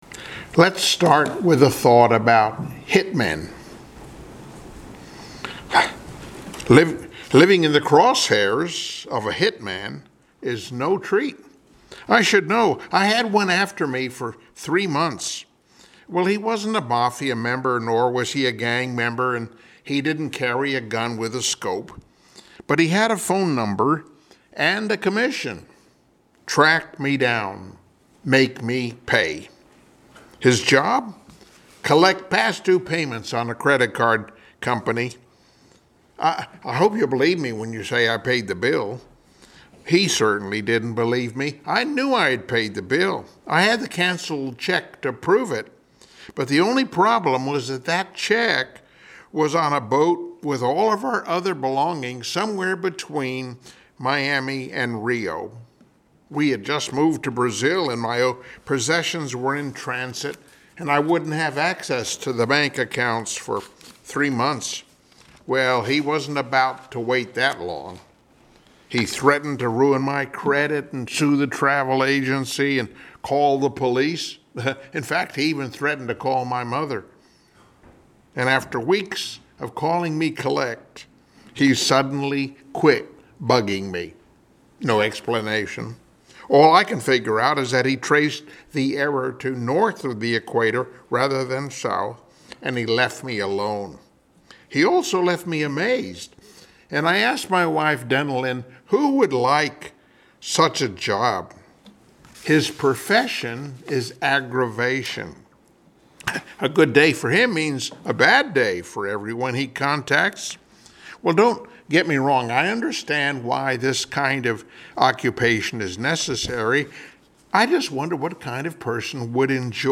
Service Type: Sunday Morning Worship Topics: The Hallway , The High Cost of Getting Even , Treat me as I Treat my Neighbor